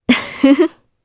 Priscilla-Vox_Happy2_jp.wav